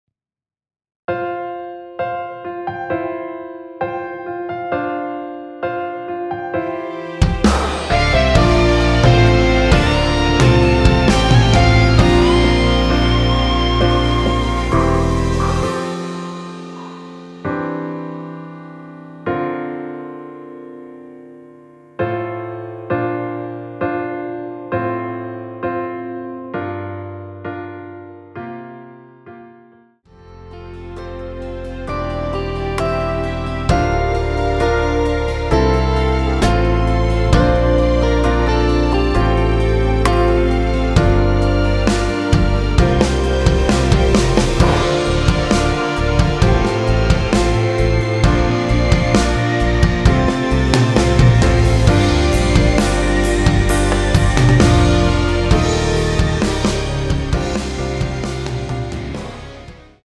원키에서(+4)올린 (1절앞+후렴)으로 진행되는 MR입니다.
앞부분30초, 뒷부분30초씩 편집해서 올려 드리고 있습니다.
중간에 음이 끈어지고 다시 나오는 이유는